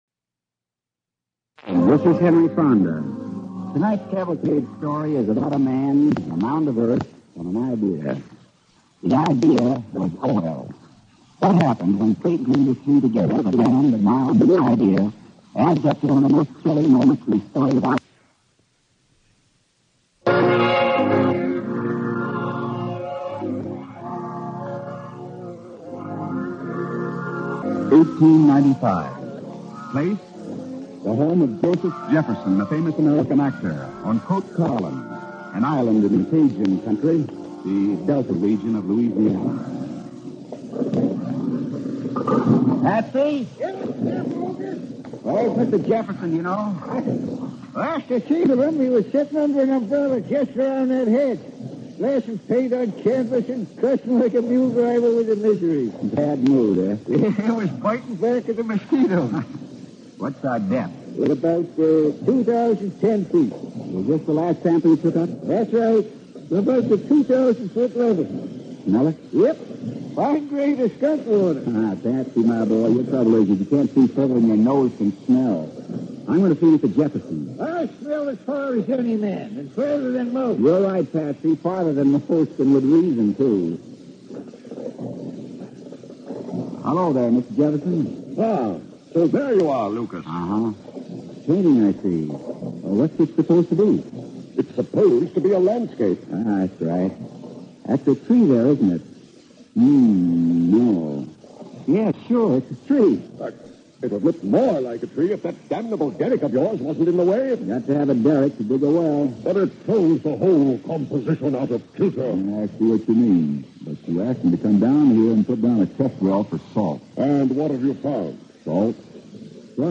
starring Henry Fonda and Gertrude Warner